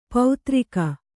♪ pautrika